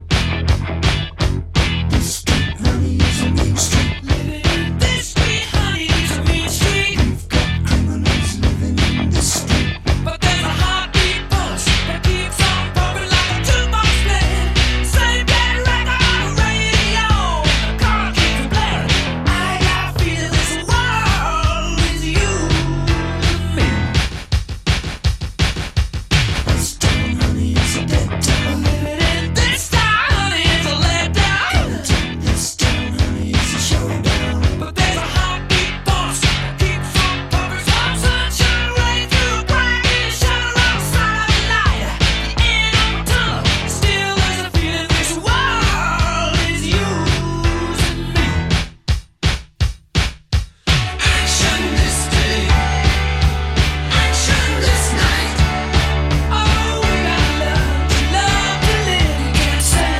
Рок
Упор сделан на танцевальный стиль.